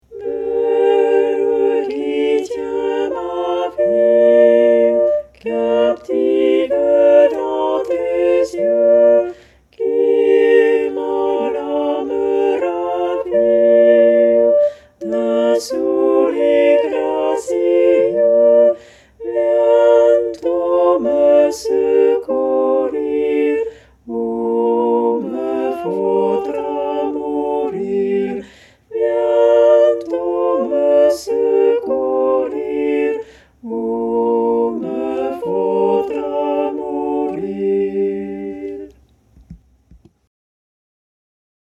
VeRSION CHANTEE
Pavane-Tutti.mp3